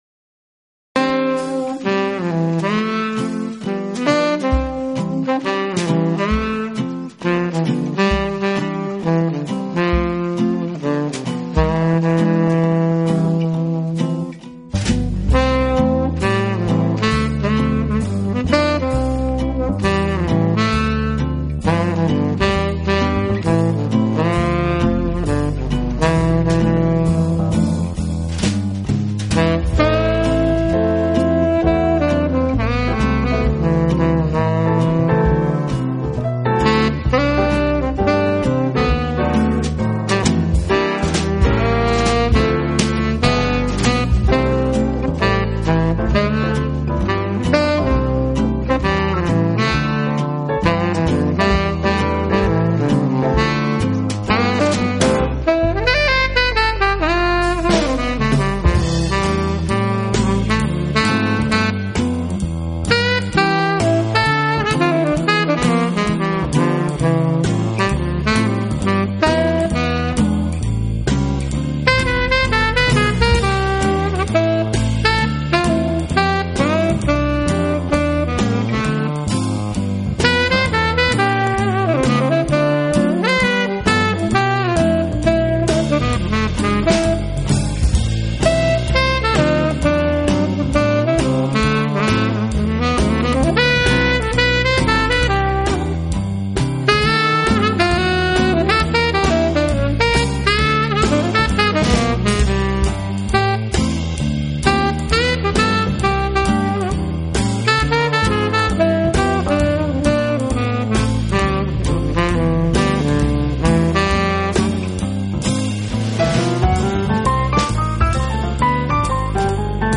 轻柔的旋律环绕四周